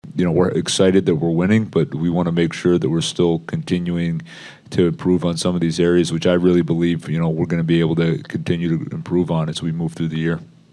Muse says the Penguins are enjoying their good start, but that’s not their only goal.